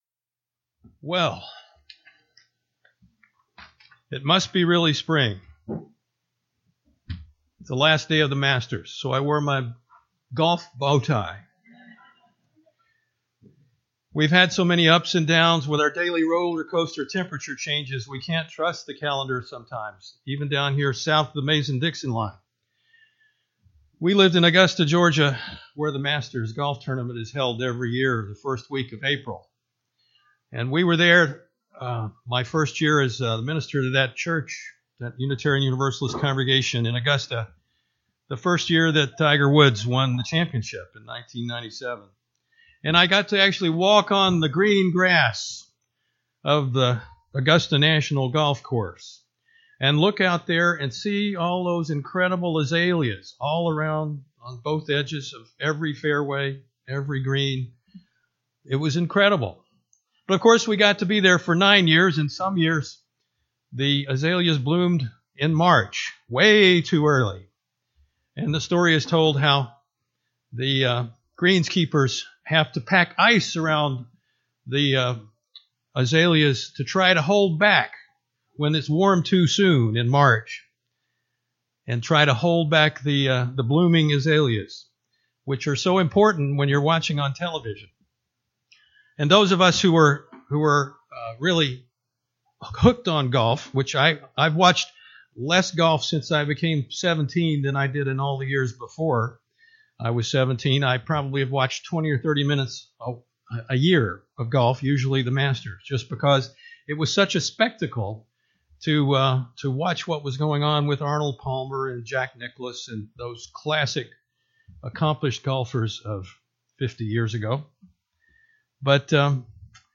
This sermon examines the profound necessity of community and the common good through the lens of seasonal and religious traditions.